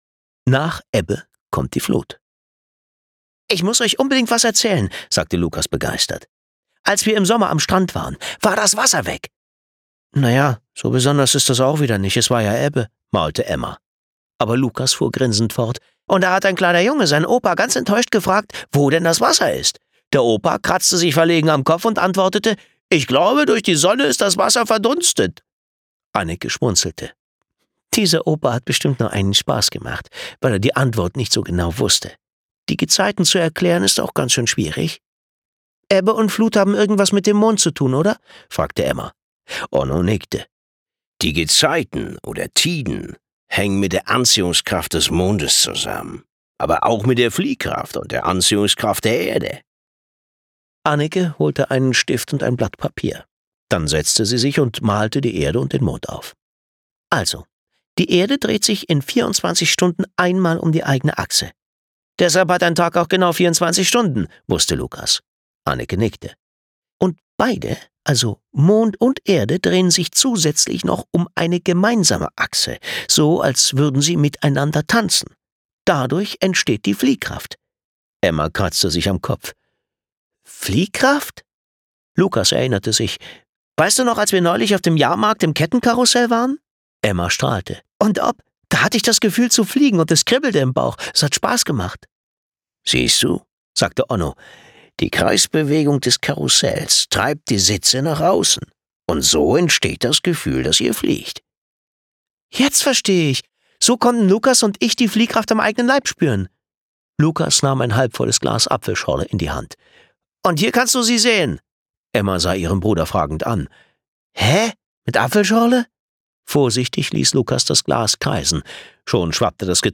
Hörbuch: Die Nordseedetektive.